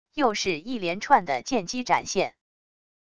又是一连串的剑击展现wav音频